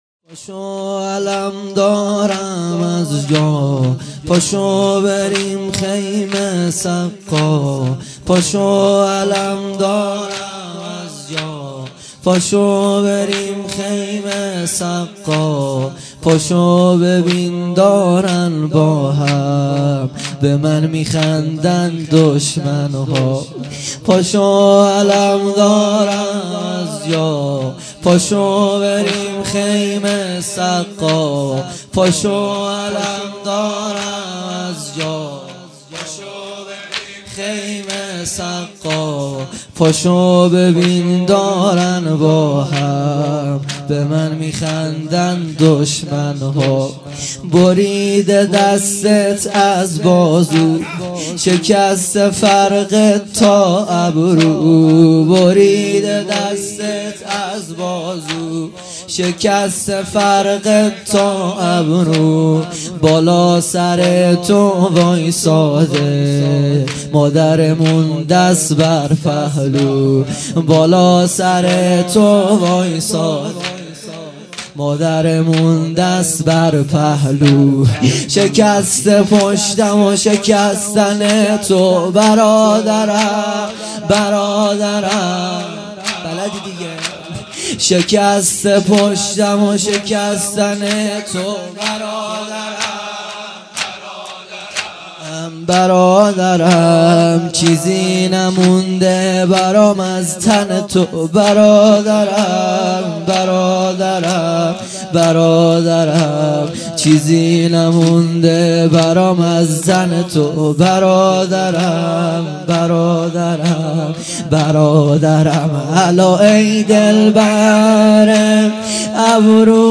زمینه | پاشو علمدارم از جا